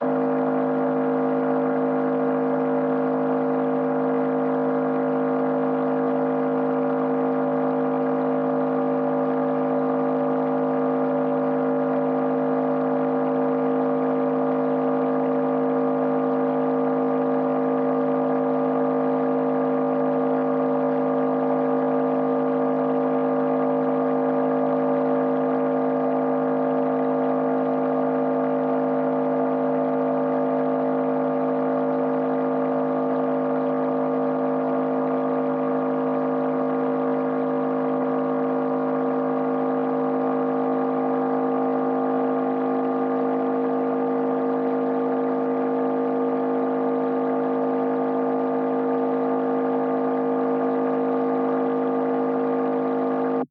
Here is “jack and jill” recited twice in this recording below.
Â I had my feet on the coil, was thinking “jack and jill” and recorded via the induction loop receiver. Â There is less noise in this type of wave and the subtle changes that comes from speak thinking recordings is easily detected.